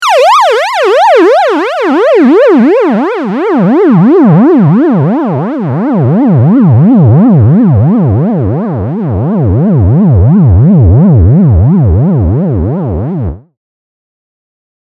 LFO auf Pitch, Geschwindigkeit nach Bedarf. Envelope mit langsamem Decay auf Pitch. Habe mir um das Soundfinetuning null Gedanken gemacht, aber darum geht's ja hier auch nicht, sondern darum, dass einmal die Tonhöhe schnell moduliert wird und zeitgleich insgesamt langsam von hoch nach tief moduliert.